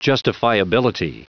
Prononciation du mot justifiability en anglais (fichier audio)
Prononciation du mot : justifiability